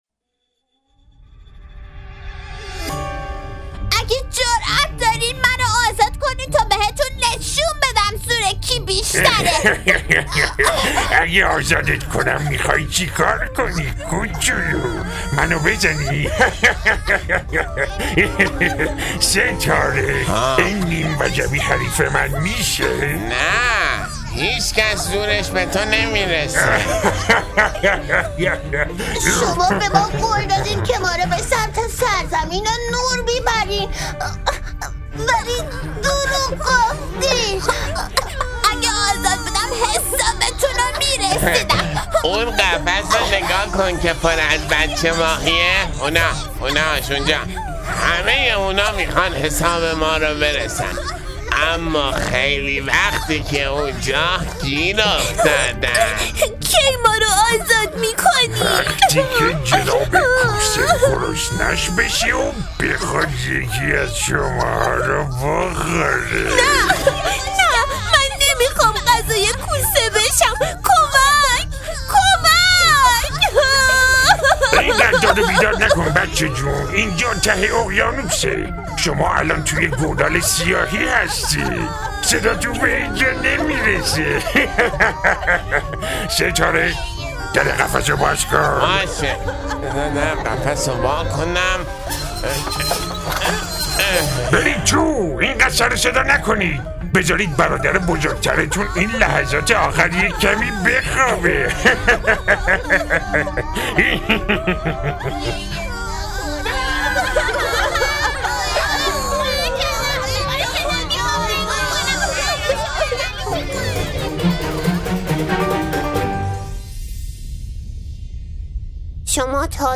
تکیه | نمایشنامه صوتی در جستجوی نور